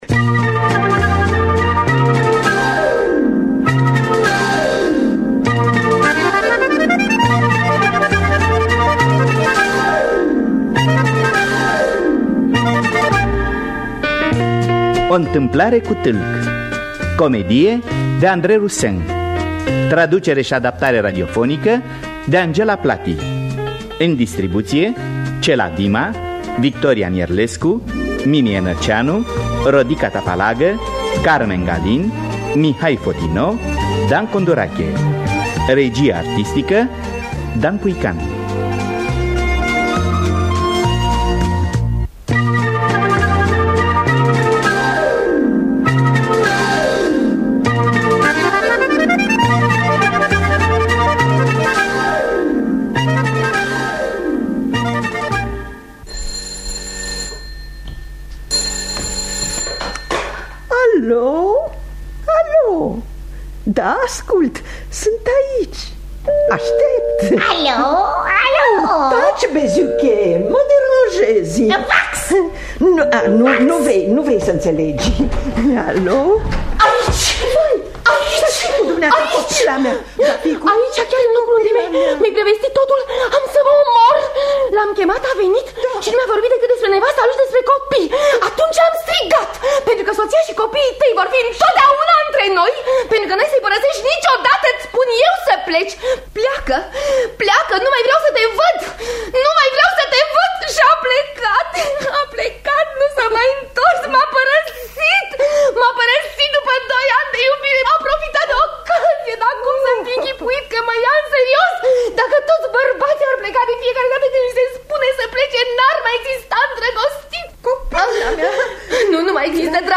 – Teatru Radiofonic Online